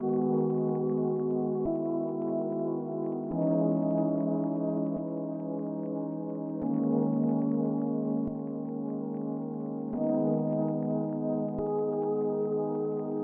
未来X果汁Wrld型循环
Tag: 145 bpm Trap Loops Synth Loops 4.46 MB wav Key : Unknown FL Studio